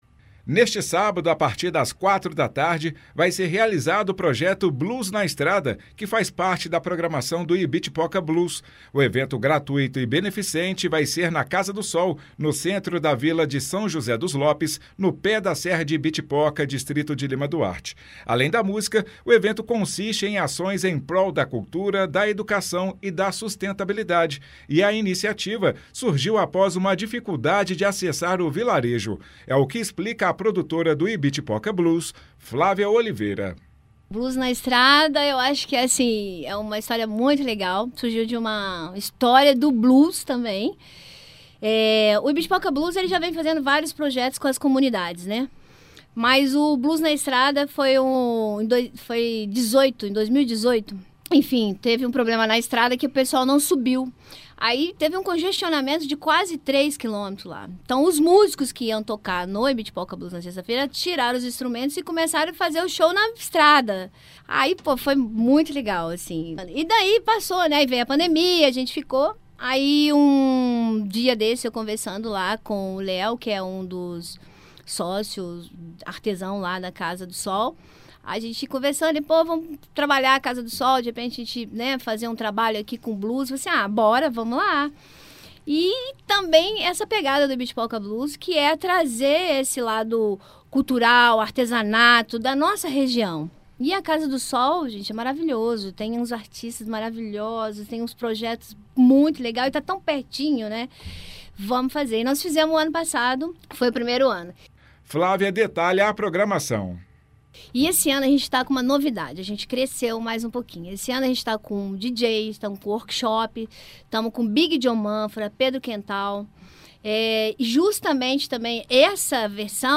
em entrevista à Itatiaia.